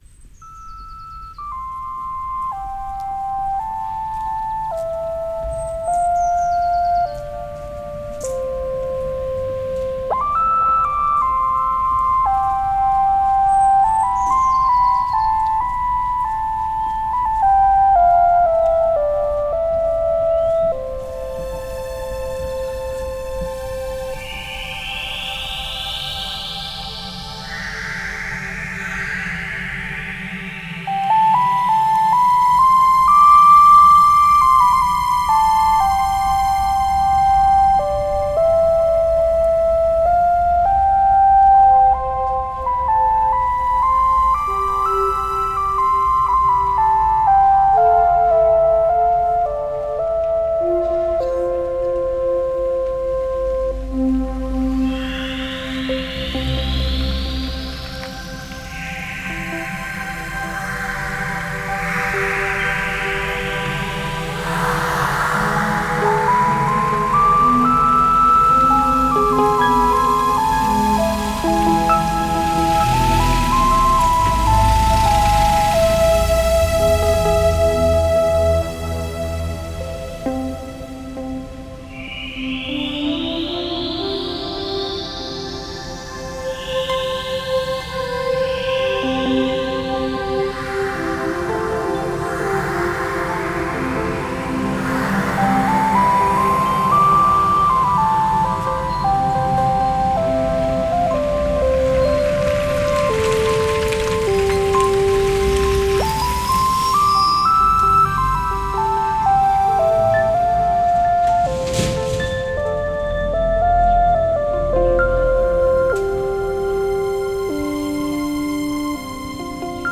����������� ������ - Yamaha SY99 � Yamaha Motif XF7.